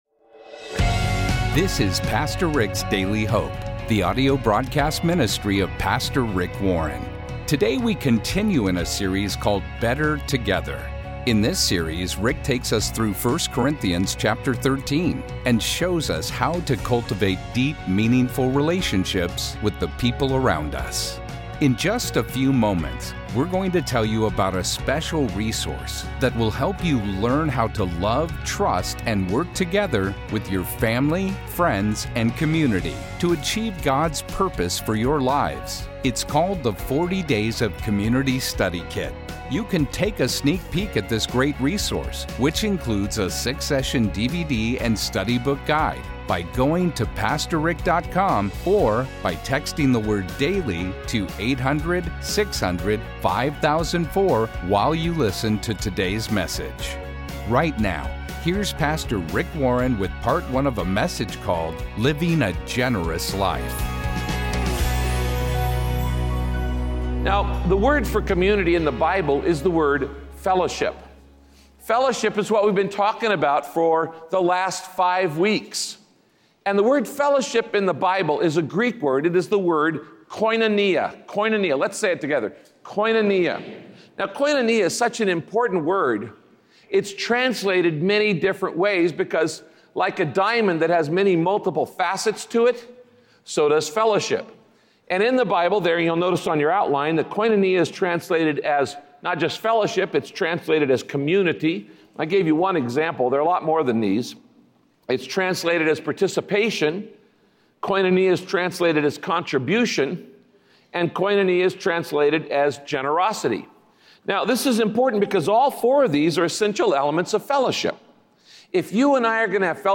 Listen to Pastor Rick as he teaches that generosity isn’t just about giving money, but also about investing time and energy into building relationships with those around you. By doing so, you can help create a sense of community that encourages generosity.
Radio Broadcast Living a Generous Life – Part 1 The Bible says that your heart will be wherever you put your time, money, and energy.